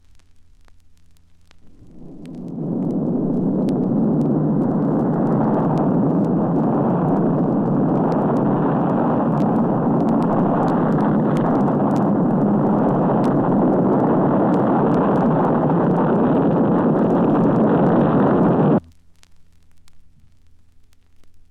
For example, here's a track titled "Atomic Bomb Explosion" from an LP the Air Force released as part of recruitment efforts in the 1950s:
What is to distinguish this sound from all manner of other loud things, including the sound of wind on a sensitive microphone?
Perhaps for you, the crackle of the LP and the quality of the recording itself evidence historicity, age – a kind of archival aura.
atomicexplosion.mp3